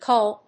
意味・対訳 キュル； キョル